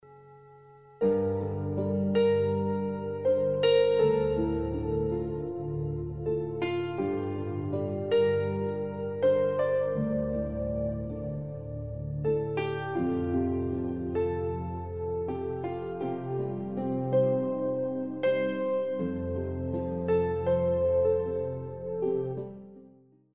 Wszystkie utwory na płycie są ze sobą połączone.